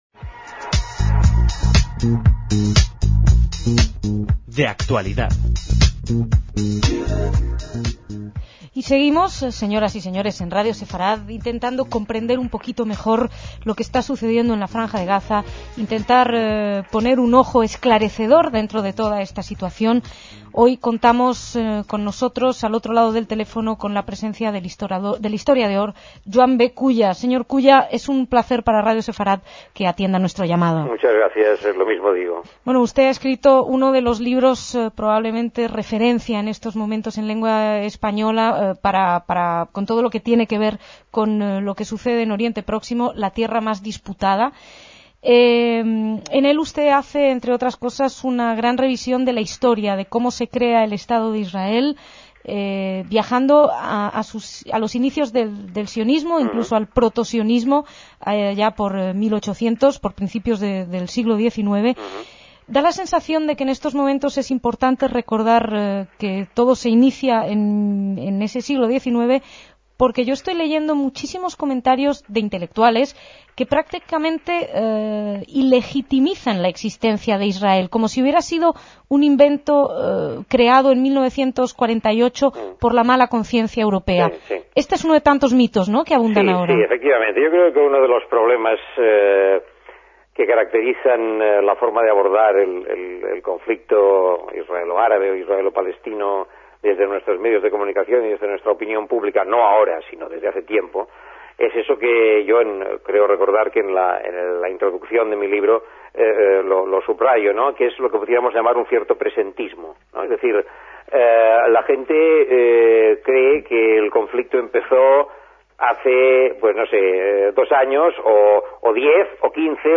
Despedimos a Joan B. Culla con una entrevista de hace casi 15 años que parece de hoy mismo